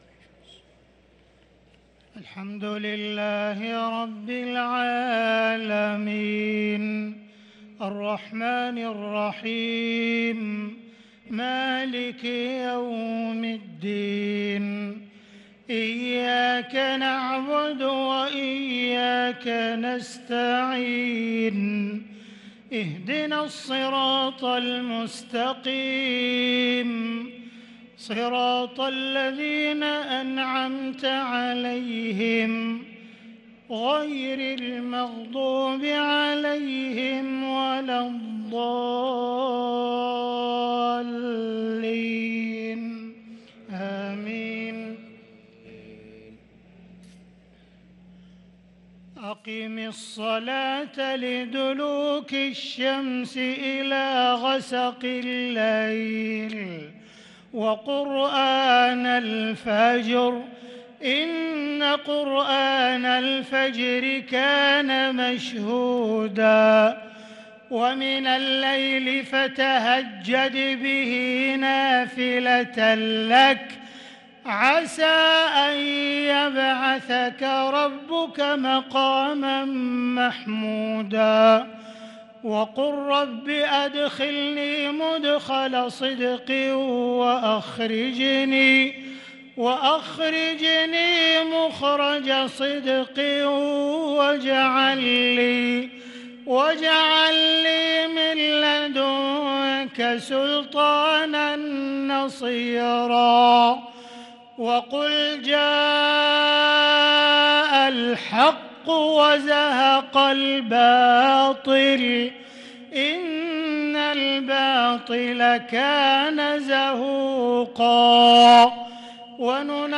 صلاة العشاء للقارئ عبدالرحمن السديس 20 رمضان 1443 هـ